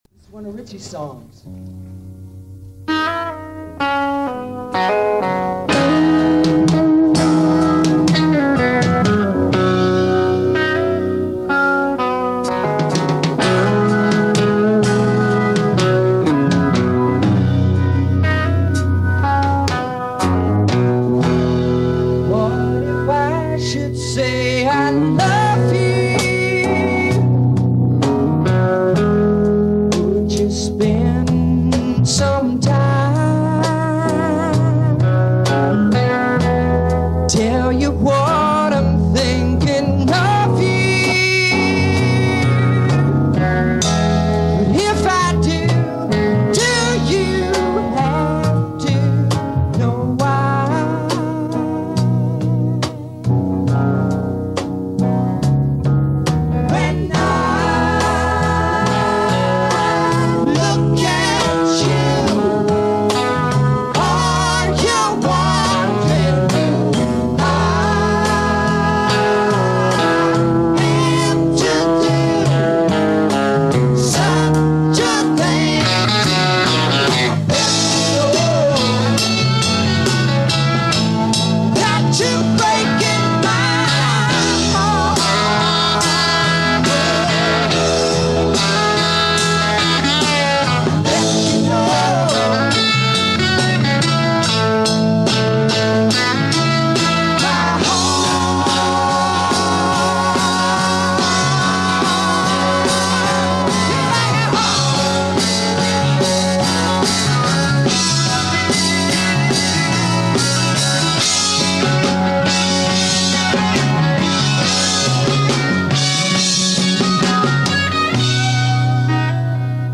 Studio session in Paris
Americana